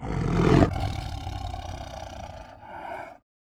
tigergrowl.wav